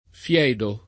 fedire v.; fedisco [fed&Sko], ‑sci, o fiedo [